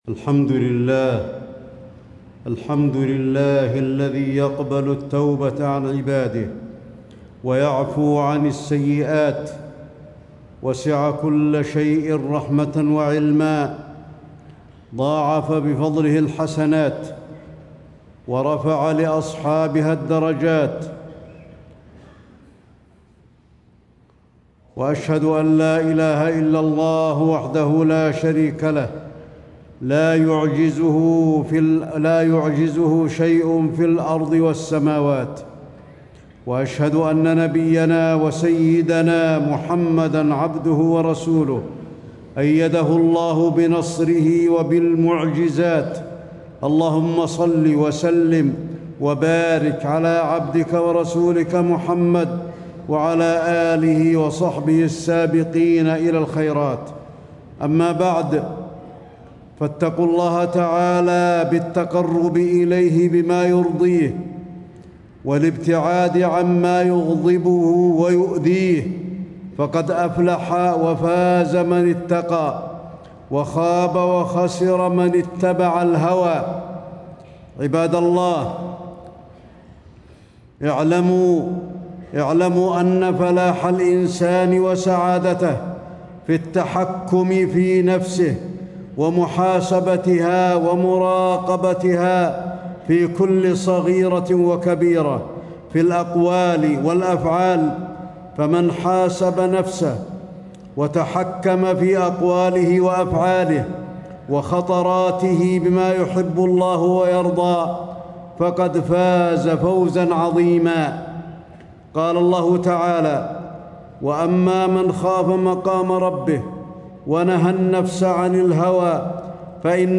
تاريخ النشر ٢٢ جمادى الأولى ١٤٣٦ هـ المكان: المسجد النبوي الشيخ: فضيلة الشيخ د. علي بن عبدالرحمن الحذيفي فضيلة الشيخ د. علي بن عبدالرحمن الحذيفي الترغيب في محاسبة النفس